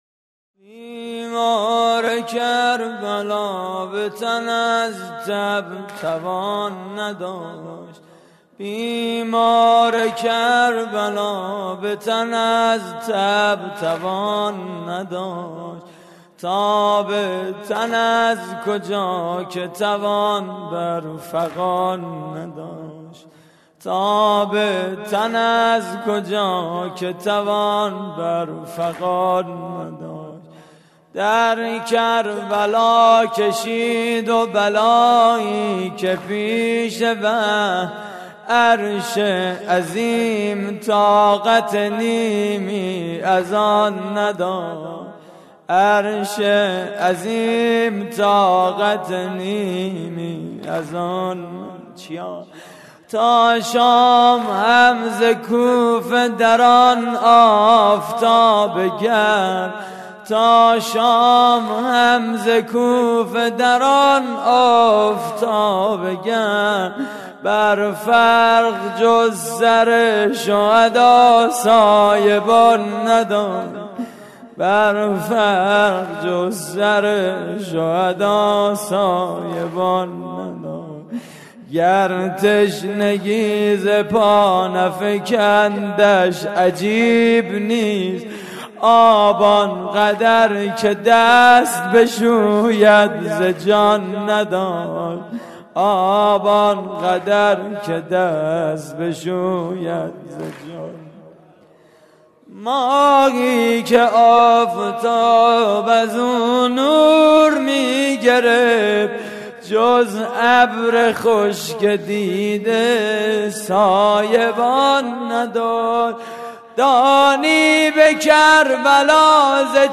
واحد: بیمار کربلا به تن از تب توان نداشت
مراسم عزاداری شهادت امام سجاد (ع)
دانشگاه صنعتی شریف